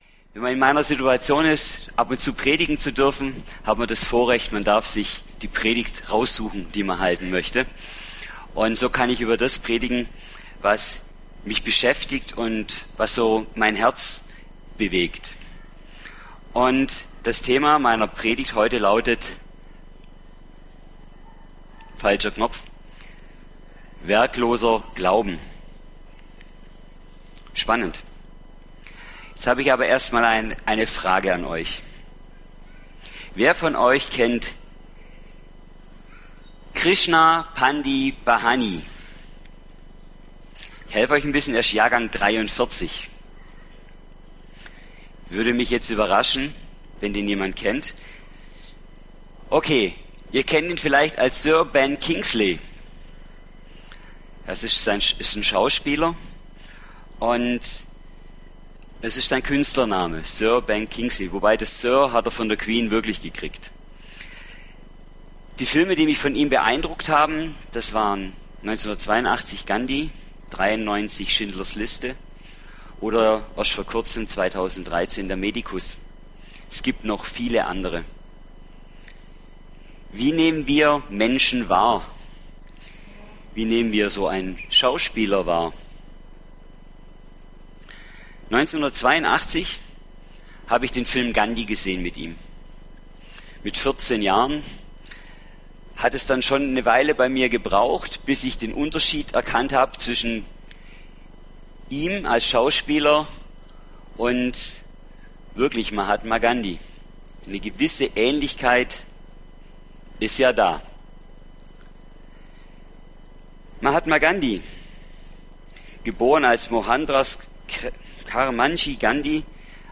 Begleitmaterial zur Predigt: